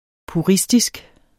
Udtale [ puˈʁisdisg ]